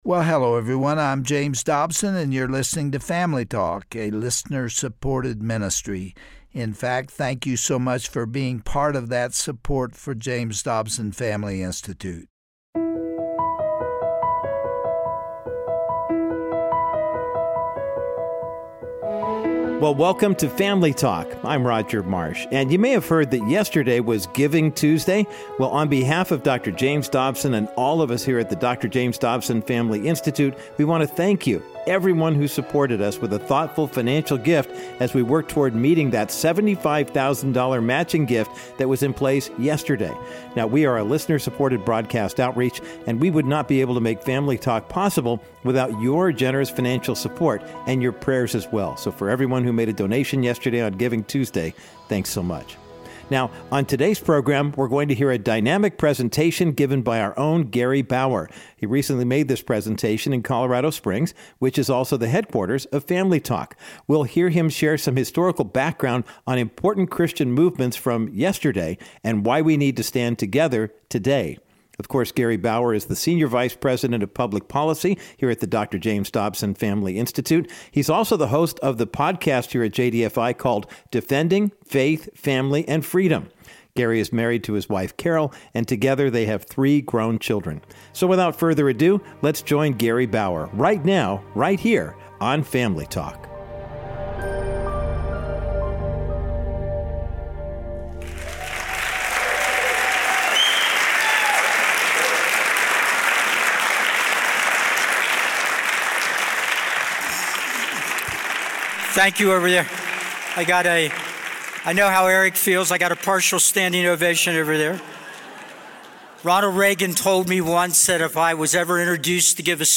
According to Gary Bauer, senior vice president of public policy for the Dr. James Dobson Family Institute, there is no historical hatred like the hatred of the Jewish people. On today’s edition of Family Talk, Gary gives a stirring speech on the cultural climate of our world.